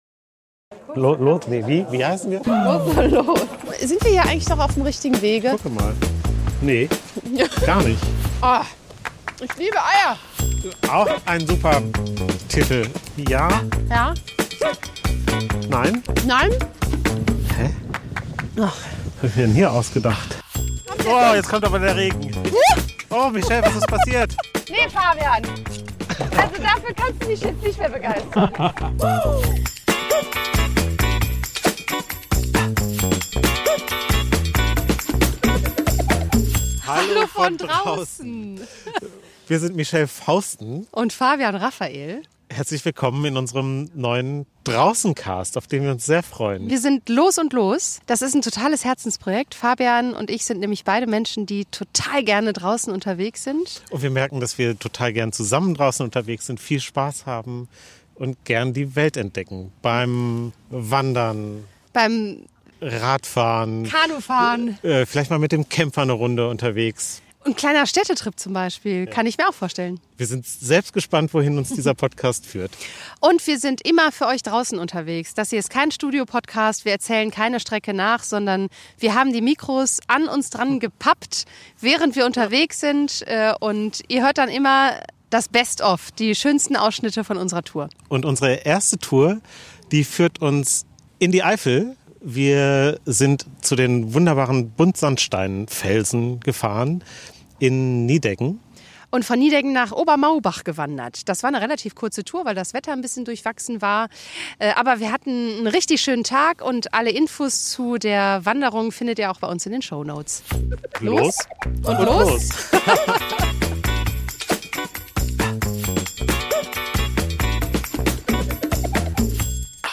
Für unsere erste Folge, sind wir (trotz saumäßiger Wetteraussichten) in die Eifel gefahren. Wir hatten alles an diesem Oktobertag: Sonne, Sturm und zum Schluss waren wir klatschnass.